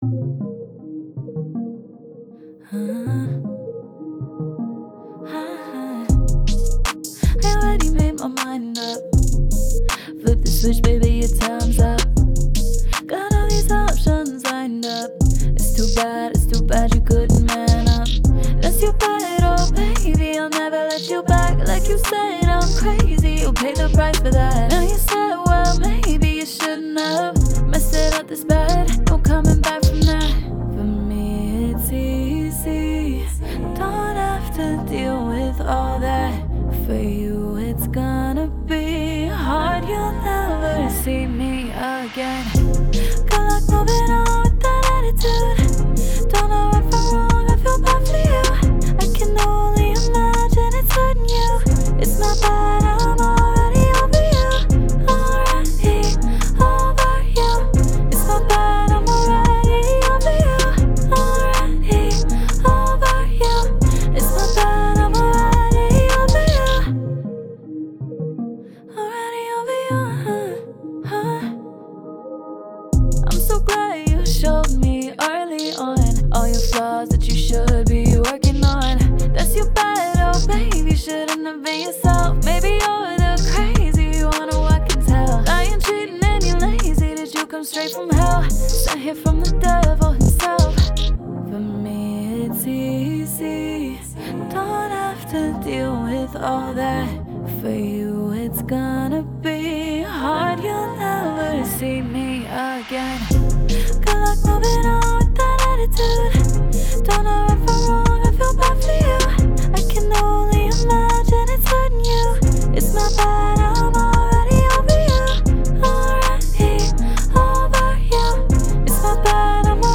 Pop, R&B
Ab Major